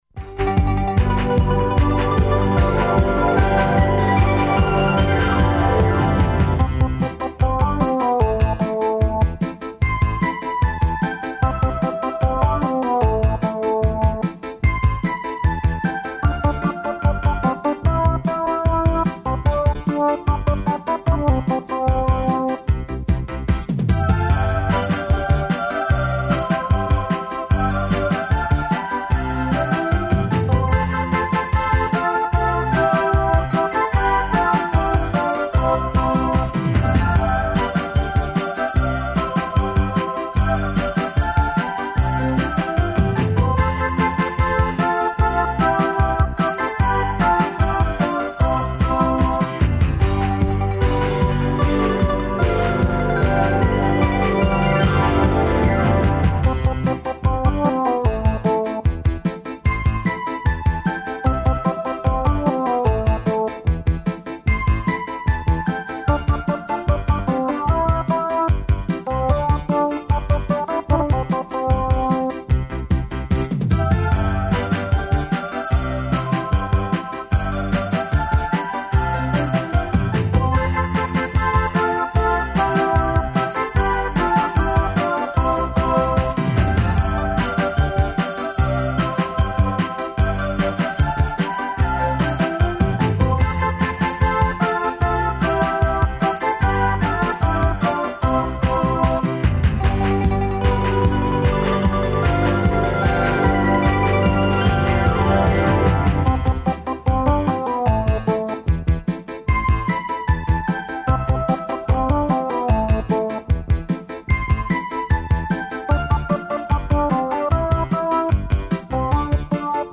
Одговоре на ова питања чућете од наших малих гостију, који се и даље играју и којима је игра најважнија ствар на свету.